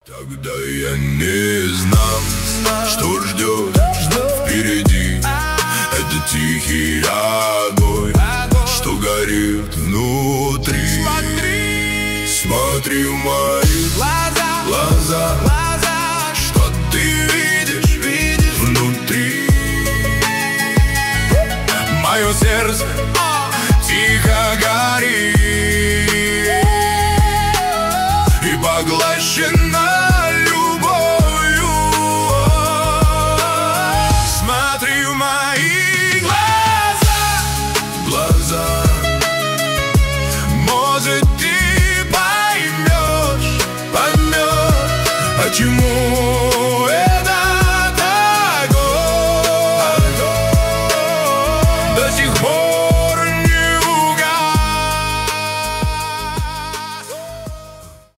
Emotional Love Story Song (Deep Voice Rap)
Жанр: эмоциональный рэп / country rap